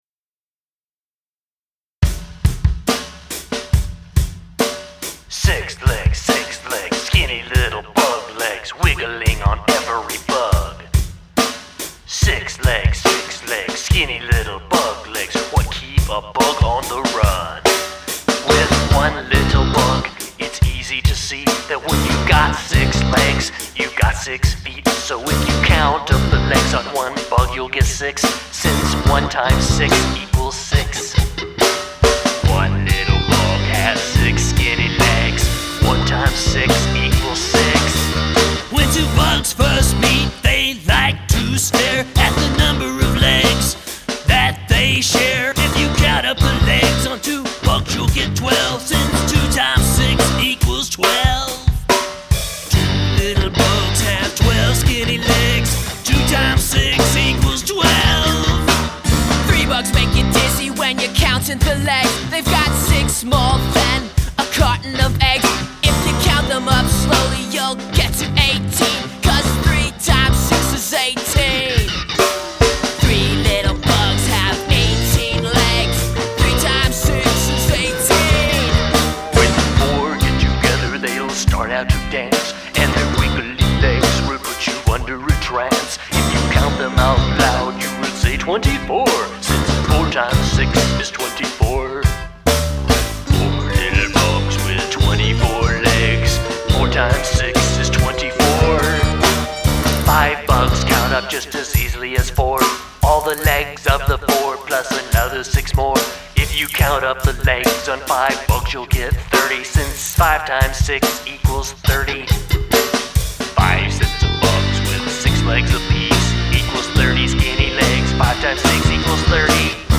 only more rockin' and trippier.